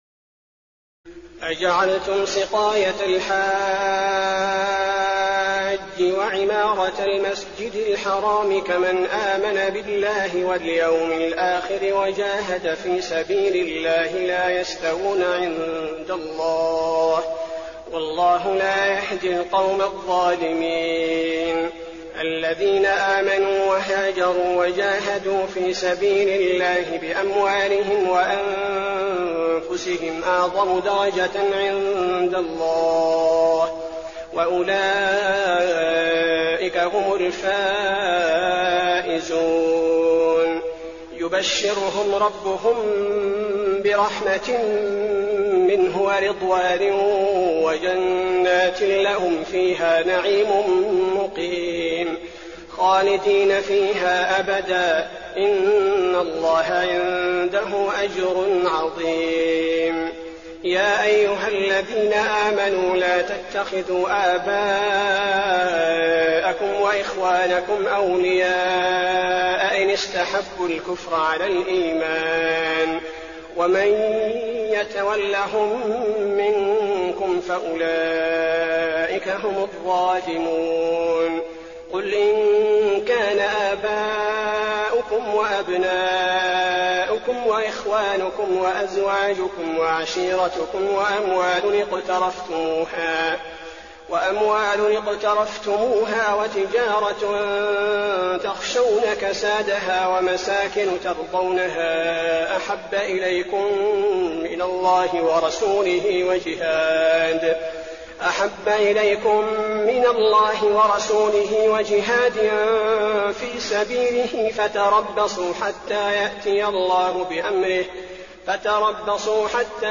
تراويح الليلة العاشرة رمضان 1422هـ من سورة التوبة (19-82) Taraweeh 10 st night Ramadan 1422H from Surah At-Tawba > تراويح الحرم النبوي عام 1422 🕌 > التراويح - تلاوات الحرمين